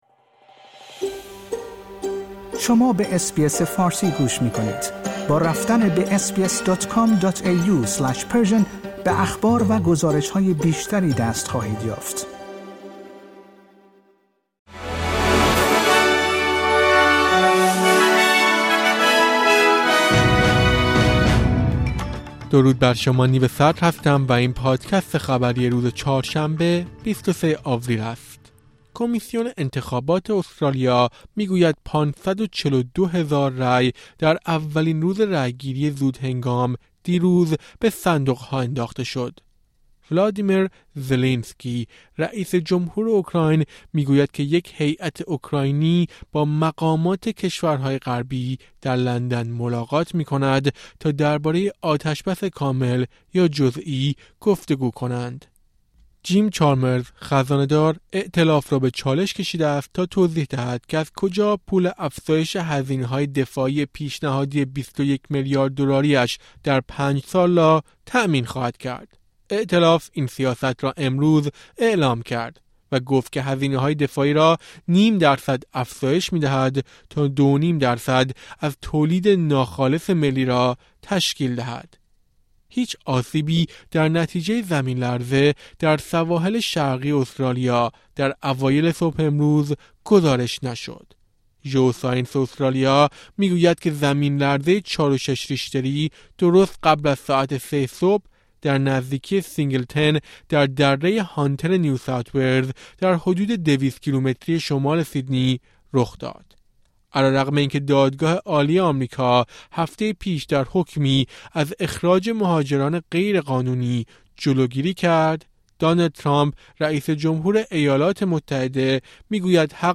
در این پادکست خبری مهمترین اخبار امروز چهارشنبه ۲۳ آپریل ارائه شده است.